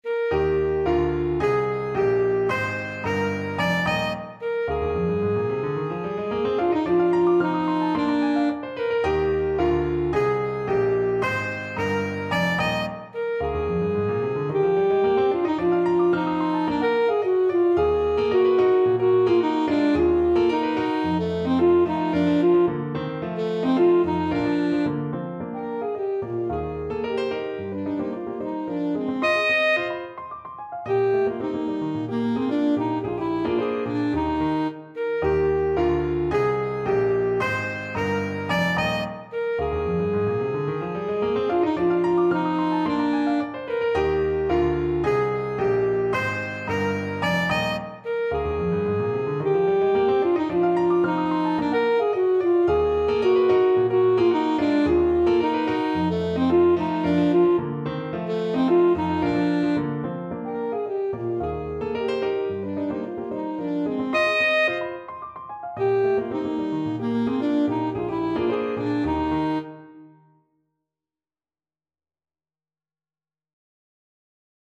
Alto Saxophone
2/4 (View more 2/4 Music)
Allegro con energia = c. 110 (View more music marked Allegro)
Classical (View more Classical Saxophone Music)
Cuban
picotazosASAX.mp3